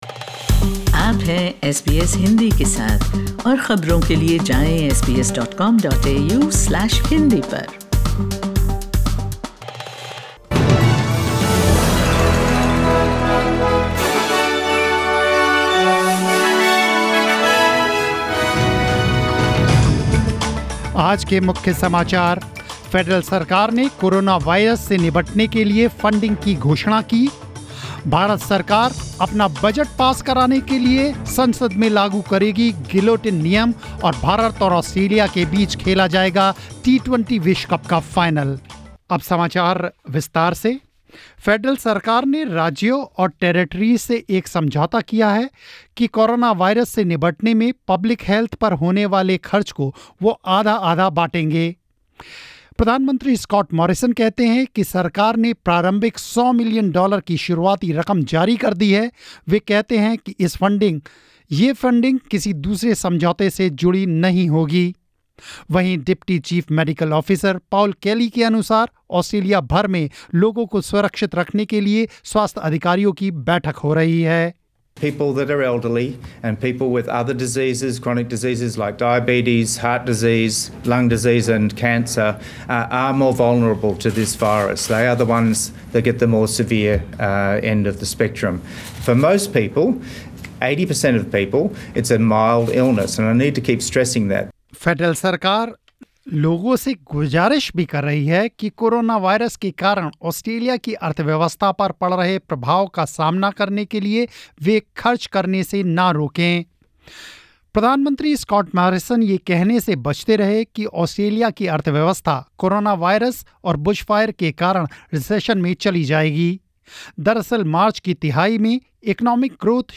News in Hindi 6th March 2020